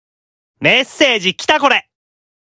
Leviathan_Chat_Notification_(NB)_Voice.ogg.mp3